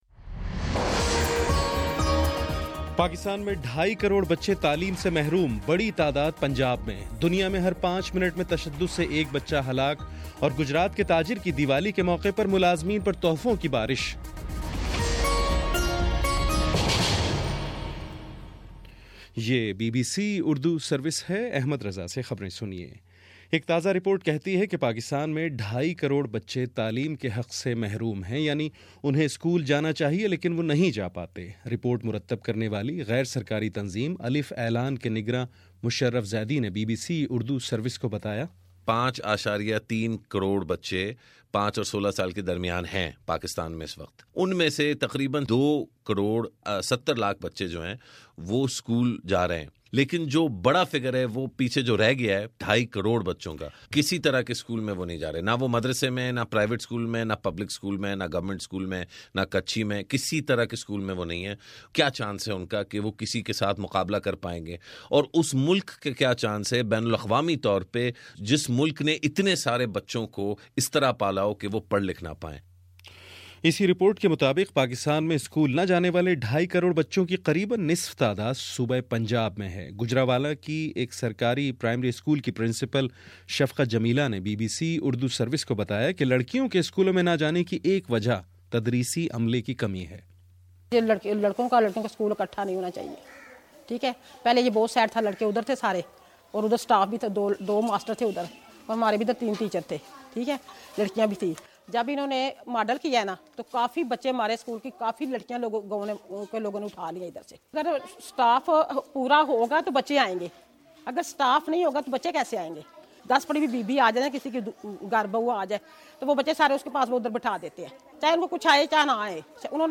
دس منٹ کا نیوز بُلیٹن روزانہ پاکستانی وقت کے مطابق صبح 9 بجے، شام 6 بجے اور پھر 7 بجے